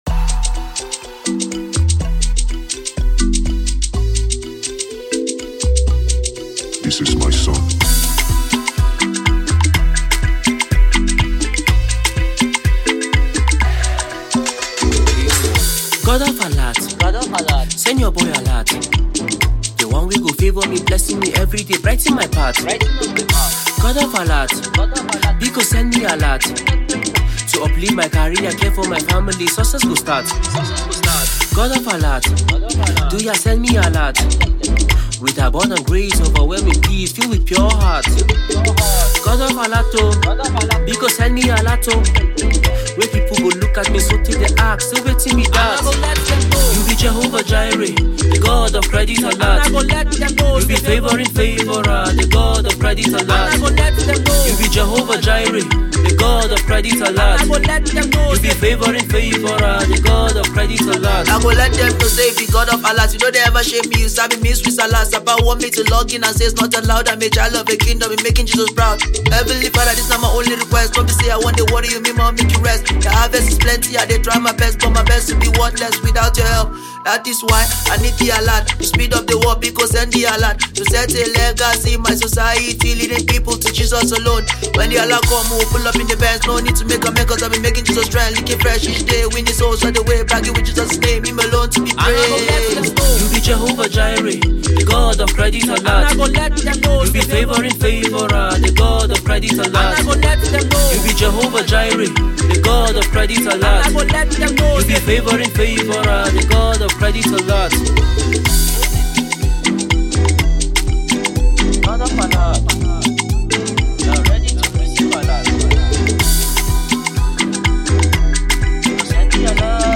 Afro-gospel music
Afro Gospel to the world